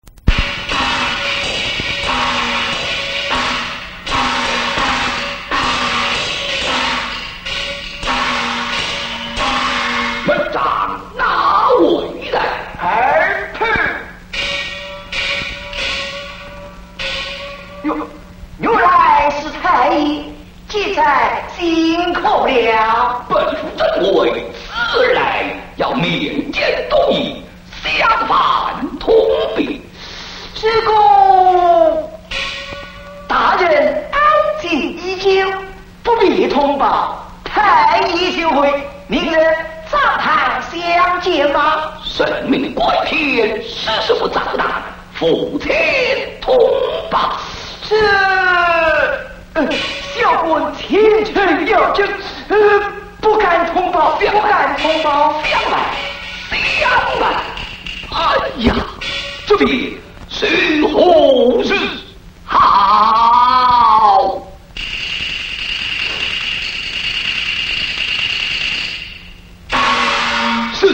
昆曲
朋友现在你在网上听到的是昆曲作品：「胜如花」，选自作品《浣纱记．寄子》，这是一首最优美的昆曲戏剧插曲。